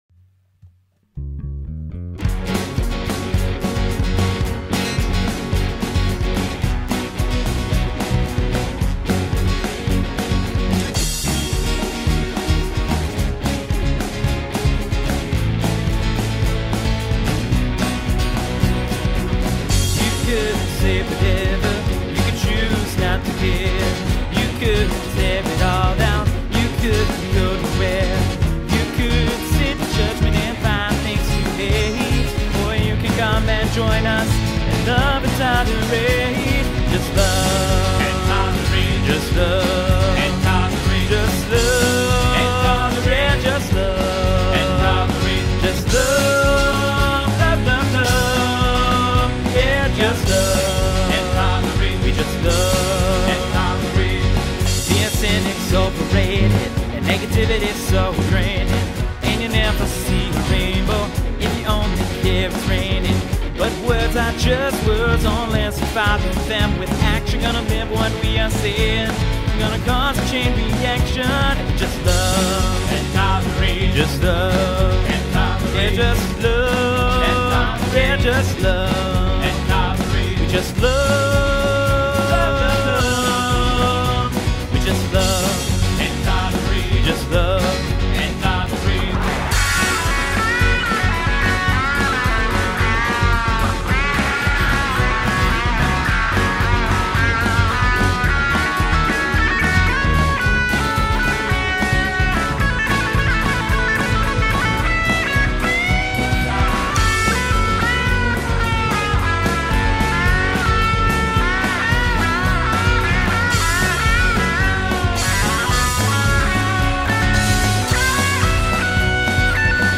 Killer Guitar solo